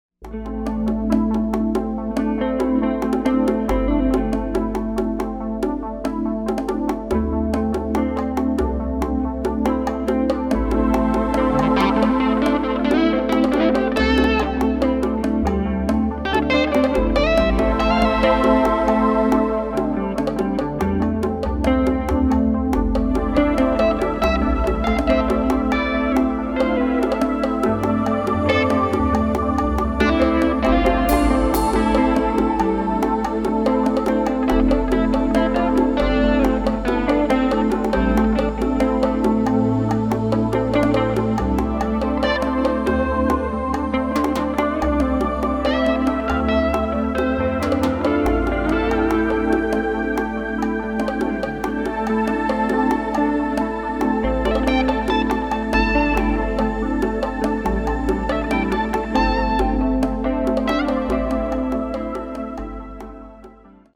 moody atmospheric grooves